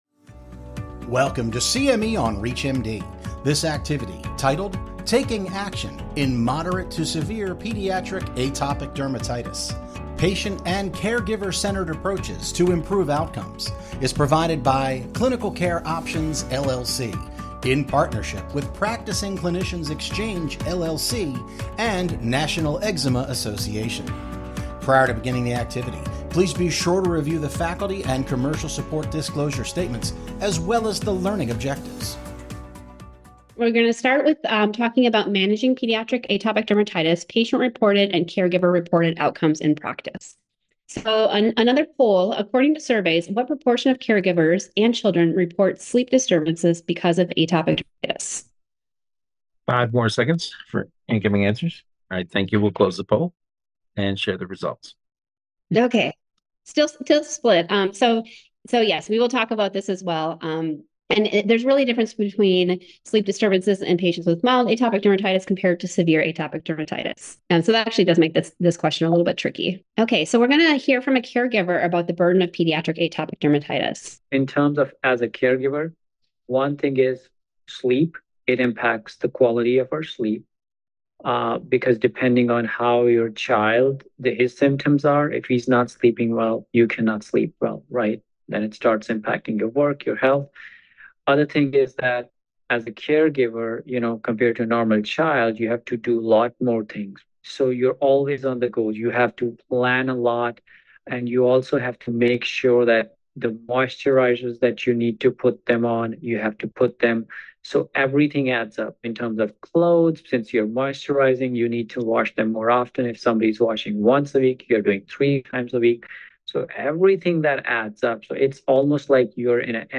On-demand webcast of expert faculty presentation on improving outcomes for ped